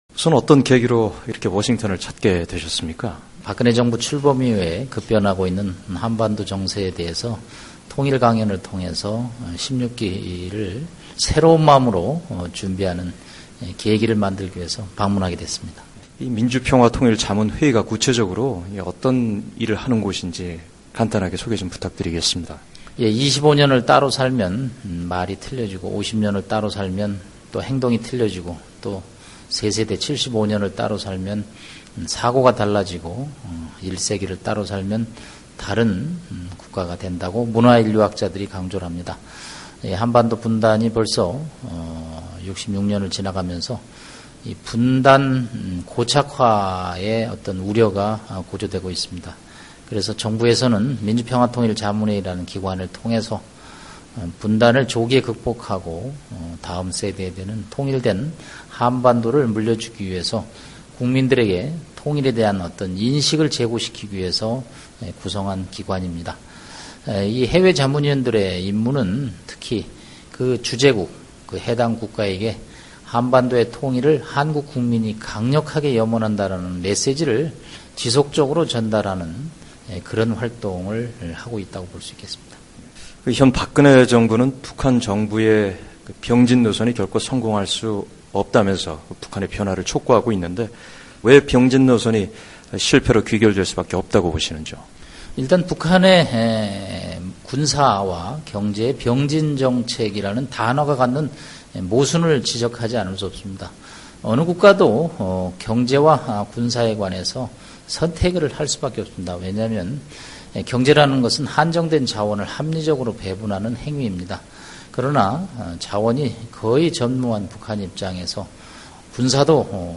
[인터뷰: 남성욱 평통 사무처장] "북한, 특사보다 진정성 보여야"